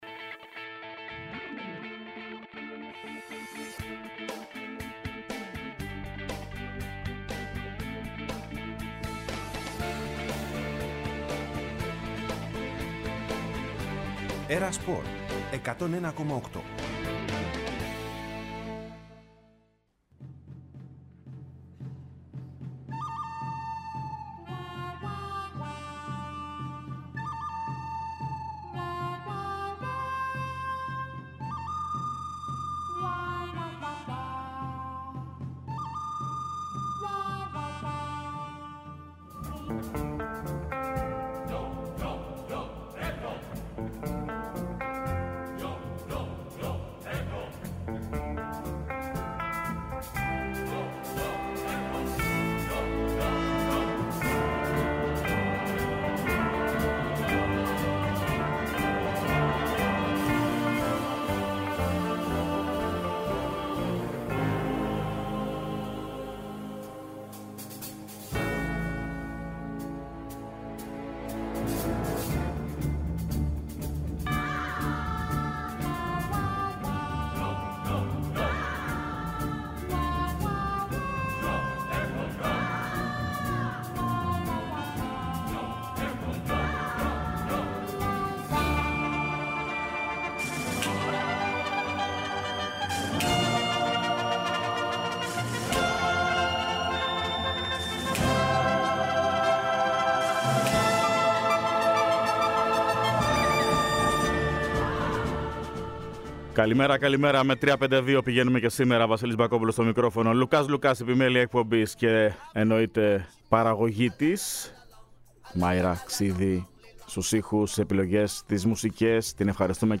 Ενημέρωση από τους ρεπόρτερ του Ολυμπιακού, του Παναθηναϊκού, της ΑΕΚ, του ΠΑΟΚ και του Άρη.